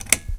switch_1.wav